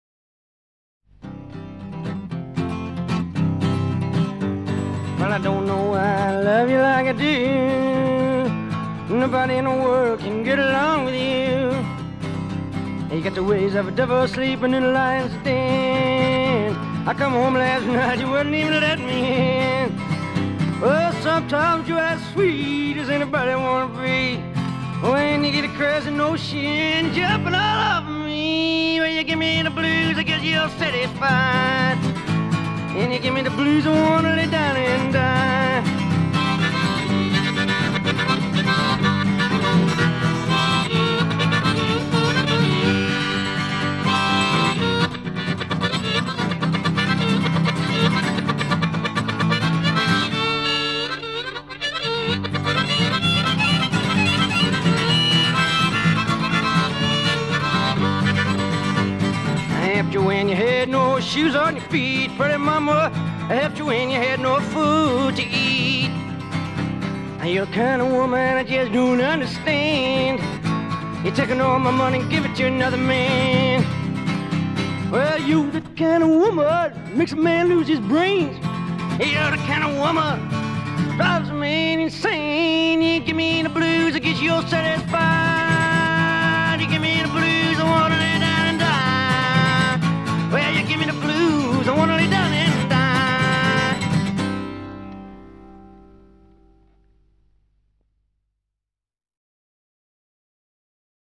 Genre: Rock, Blues, Folk, World